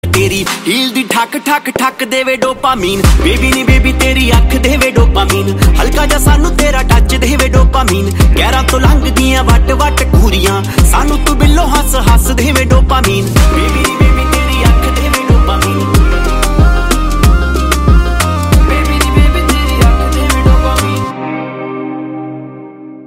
Song TypePunjabi Pop